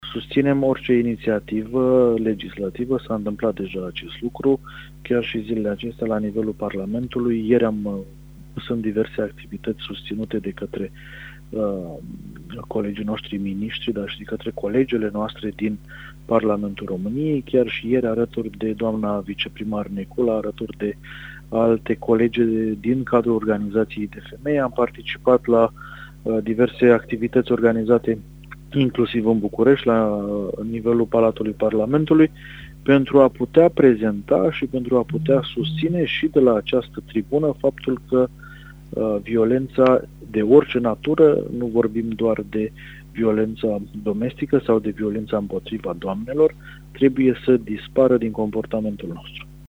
Într-un interviu amplu, acesta vorbește despre efectele „austerității selective”, despre discrepanțele dintre investițiile din Bihor și cele din Iași, dar și despre necesitatea relansării economice.